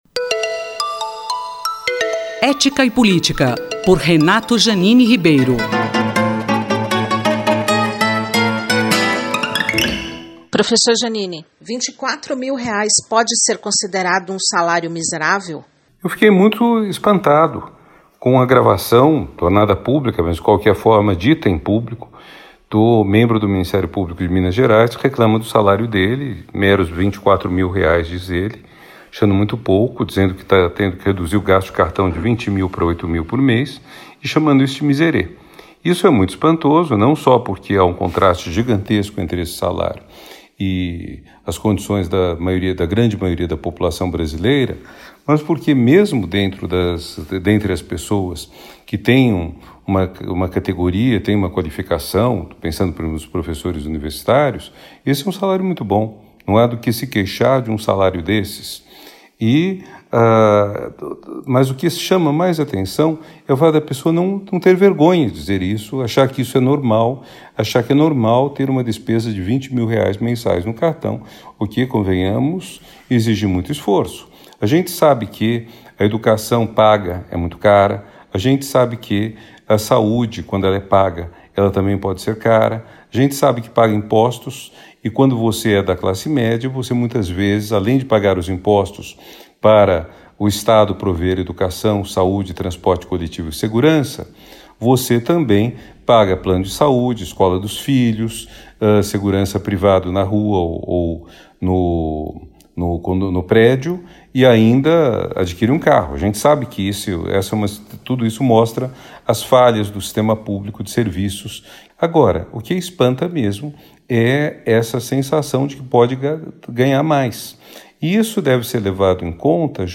Nesta semana, o colunista Renato Janine Ribeiro comenta um áudio que circulou nas redes sociais: um membro do Ministério Público Federal de Minas Gerais diz que este valor é um “miserê” e que precisaria reduzir os gastos do cartão de crédito de R$ 20 mil para R$ 8 mil.